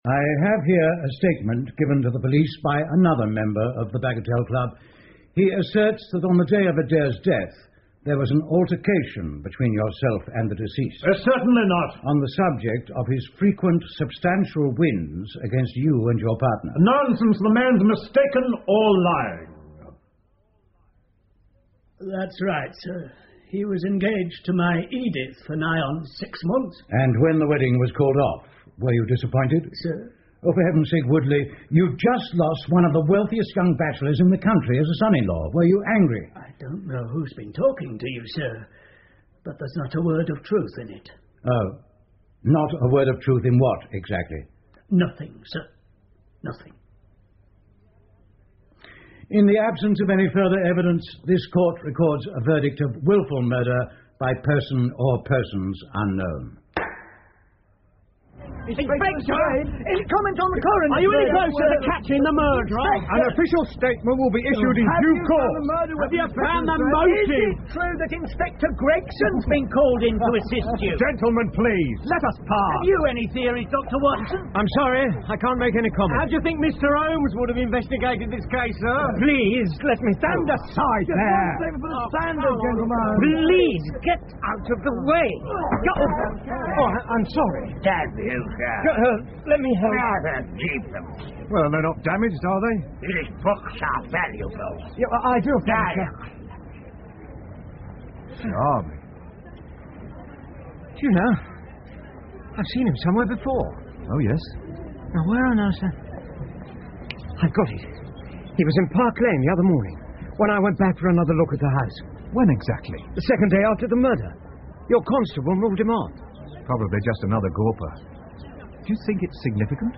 福尔摩斯广播剧 The Empty House 4 听力文件下载—在线英语听力室